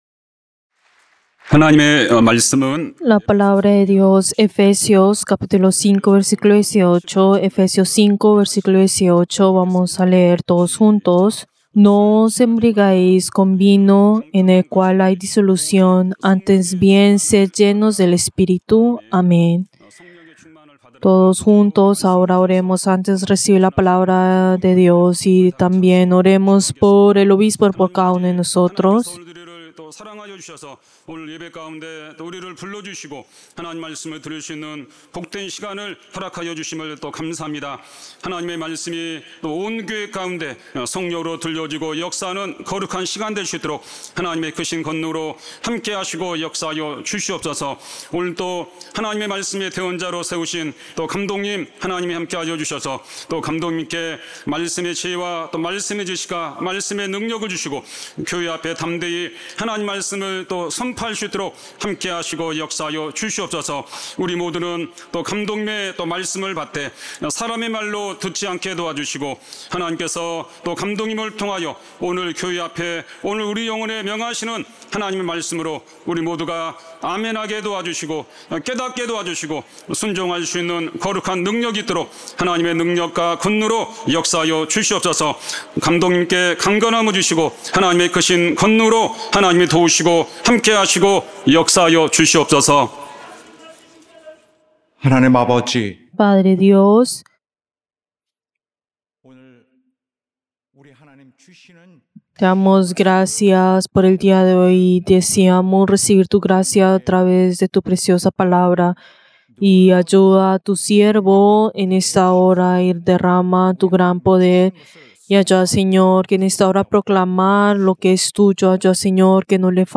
Servicio del Día del Señor del 19 de enero del 2025